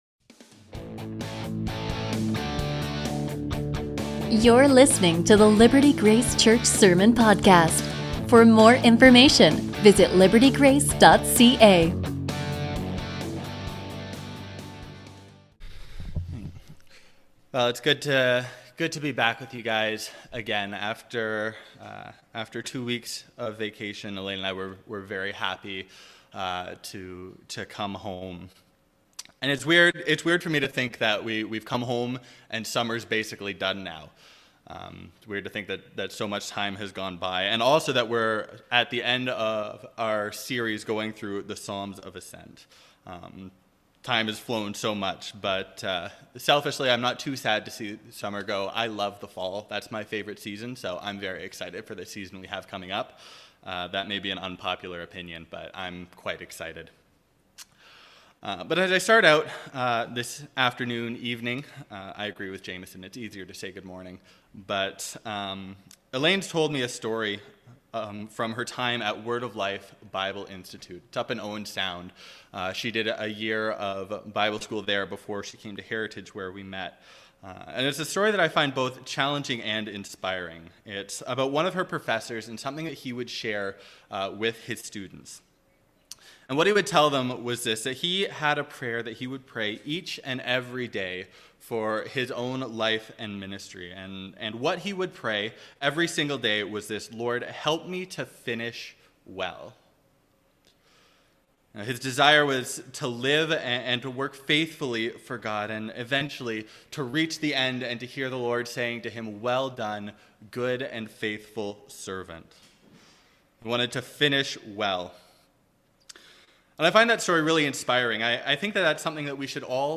A sermon from Psalm 134